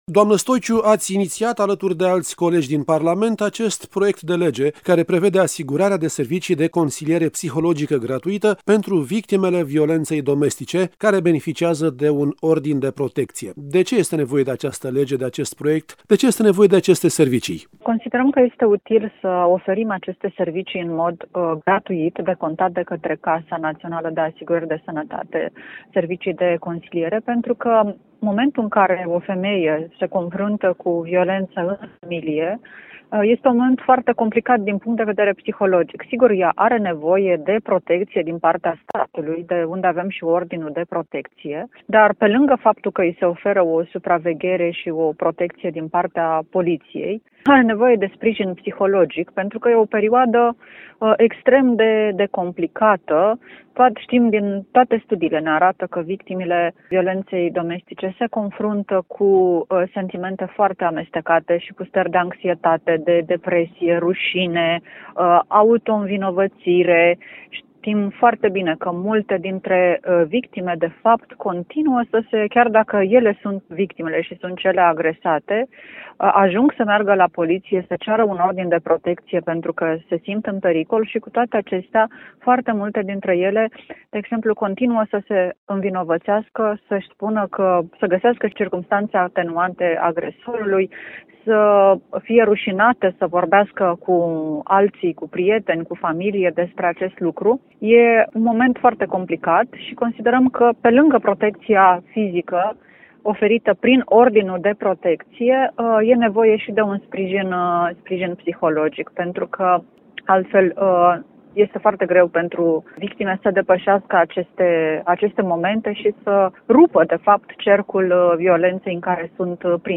a discutat subiectul cu unul dintre inițiatorii acestui proiect de lege, senatorul PSD Victoria Stoiciu, vicepreședintele Comisiei pentru drepturile omului, egalitate de șanse, culte şi minorităţi.